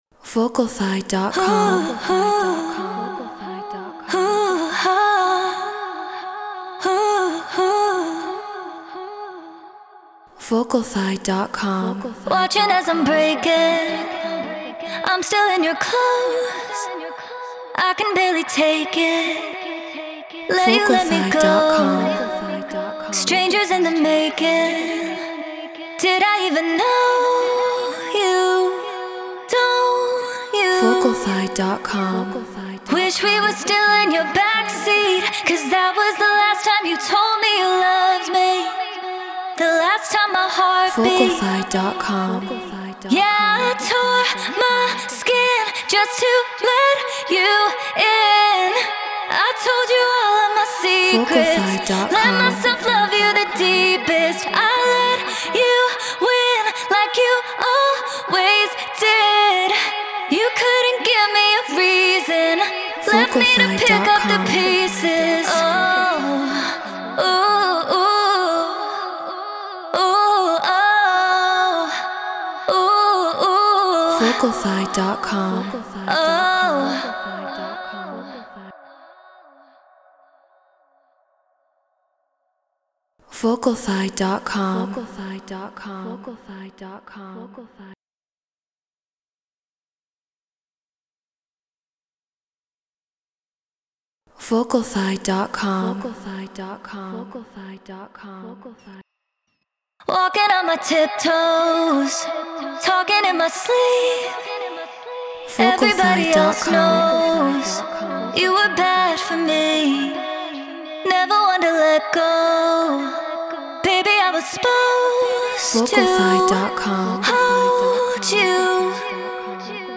Drum & Bass 175 BPM Emaj
Flea 47 Apogee Symphony Mark ii Logic Pro Treated Room